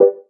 TouchpadSound_confirm.wav